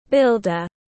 Builder /ˈbɪldər/